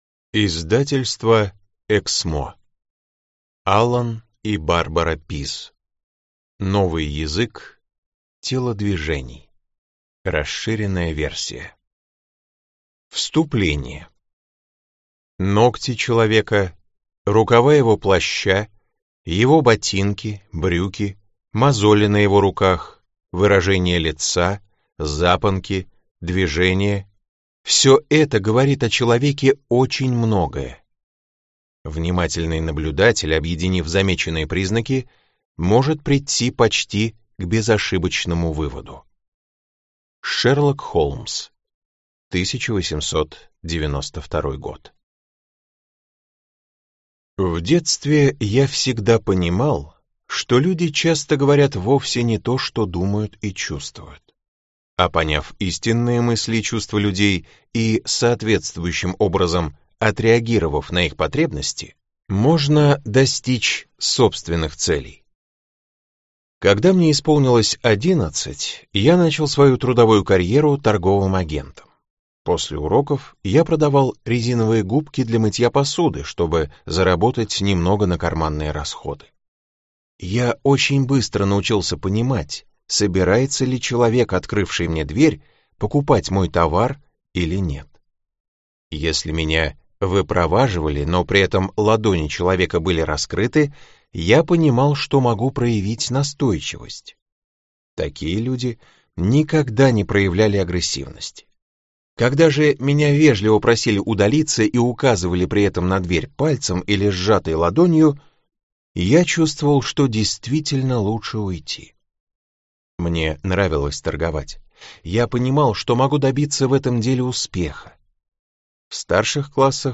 Расширенная версия» в интернет-магазине КнигоПоиск ✅ Зарубежная литература в аудиоформате ✅ Скачать Новый язык телодвижений.